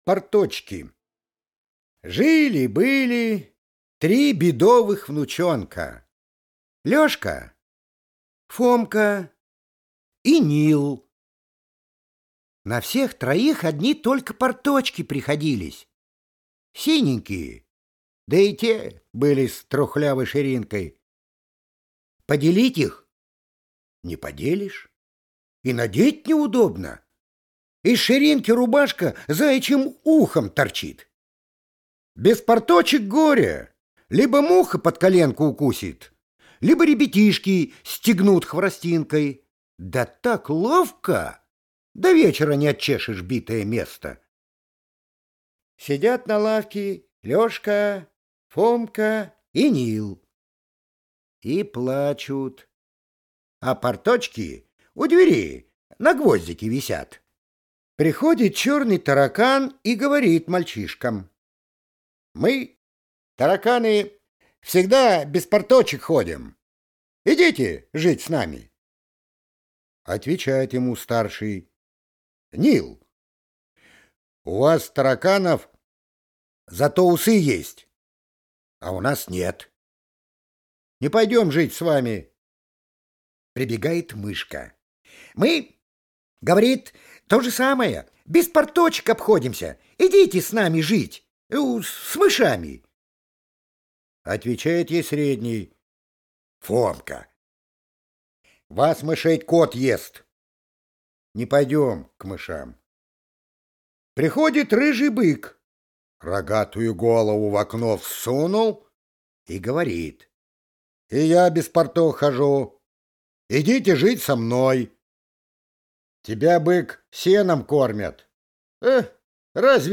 Порточки – Толстой А.Н. (аудиоверсия)
Аудиокнига в разделах